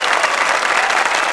Applause sounds added
Applause.wav